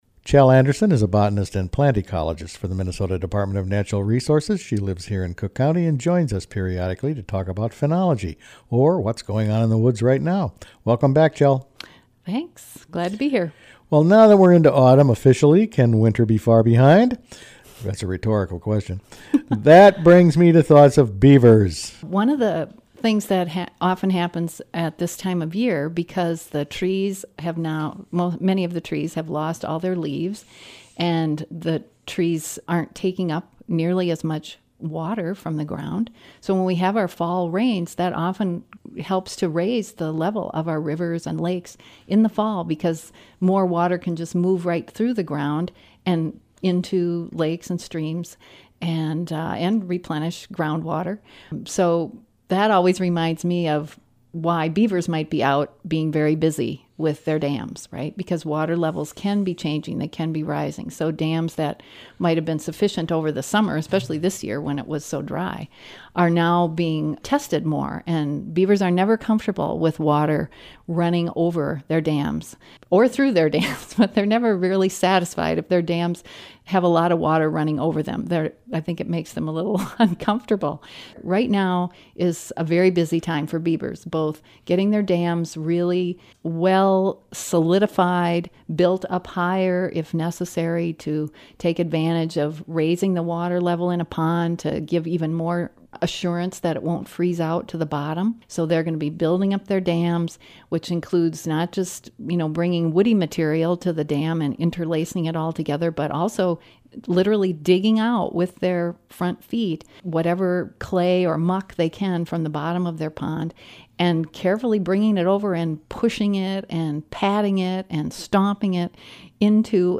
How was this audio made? Busy beavers and ballooning spiders signify fall | WTIP North Shore Community Radio, Cook County, Minnesota